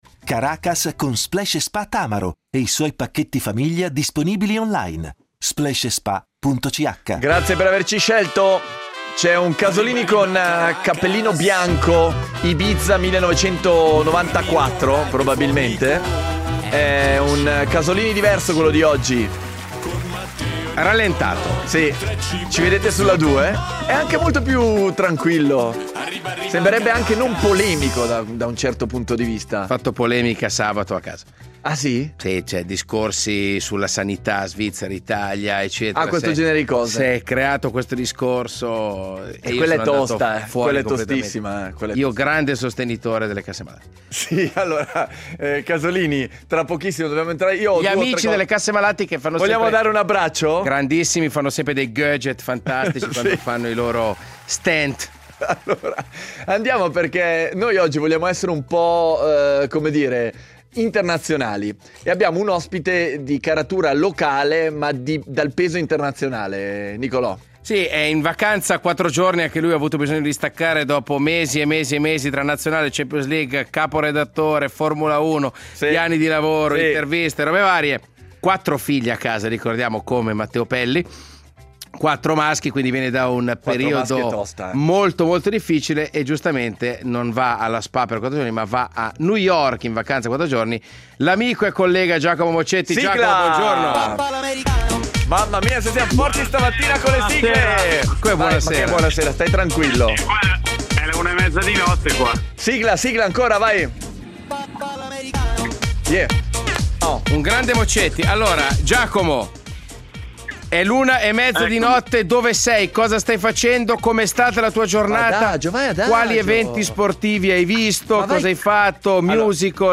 in diretta da New York City